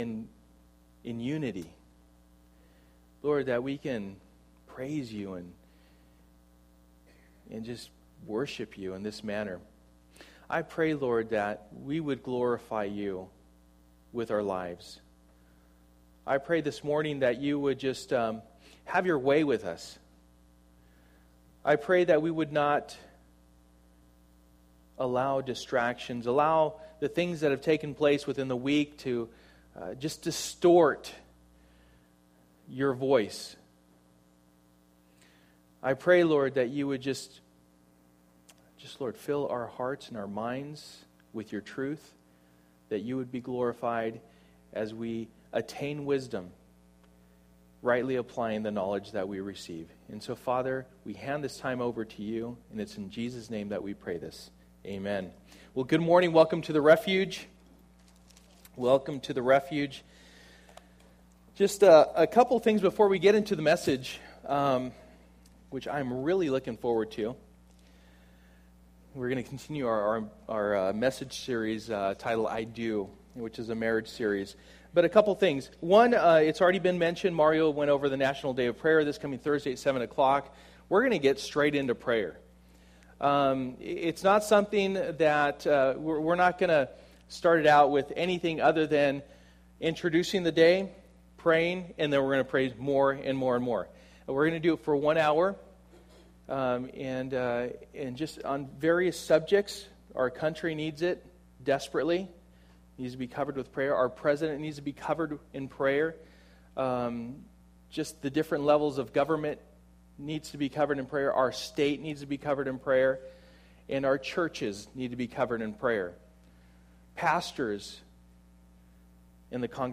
Passage: Ephesians 5:25 Service: Sunday Morning